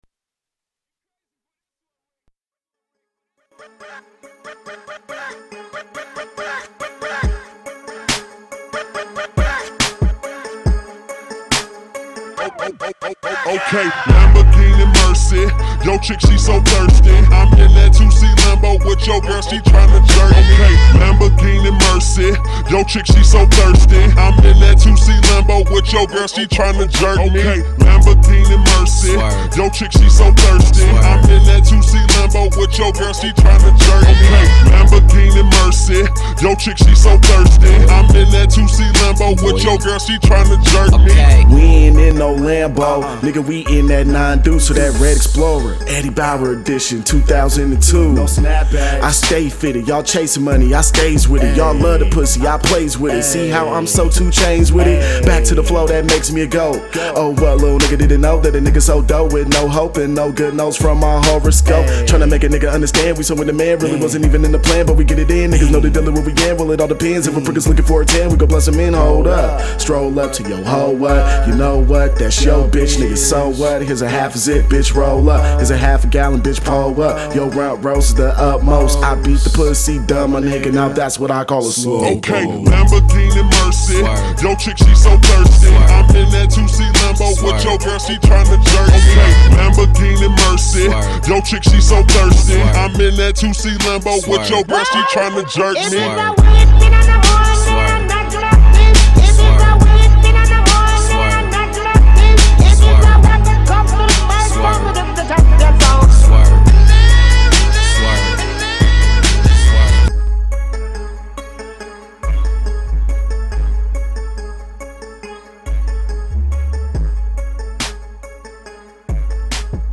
Quickie freeverse